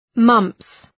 Shkrimi fonetik {mʌmps}